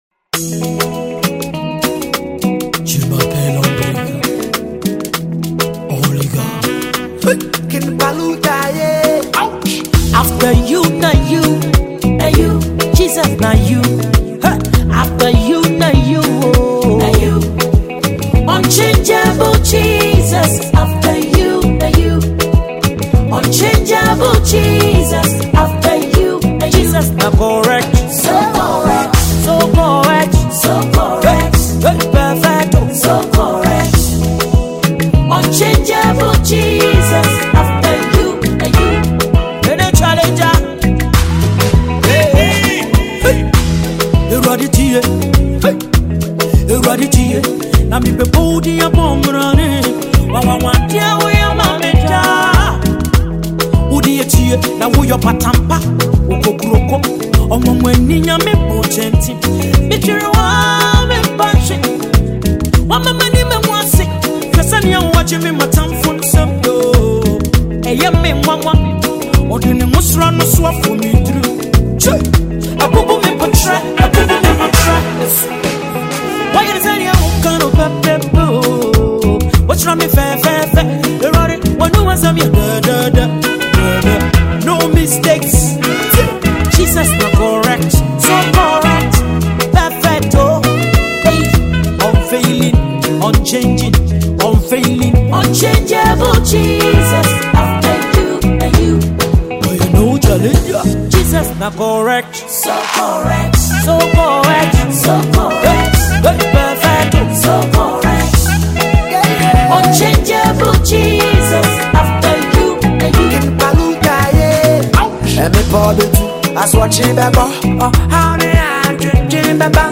a Ghanaian female gospel artist
Ghana Gospel Music